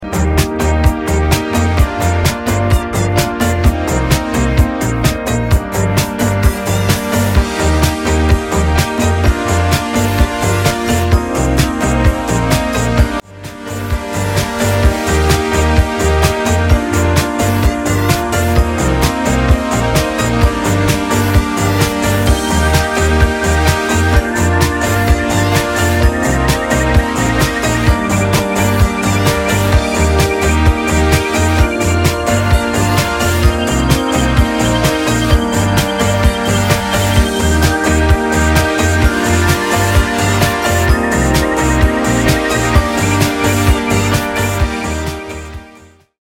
The instrumental versions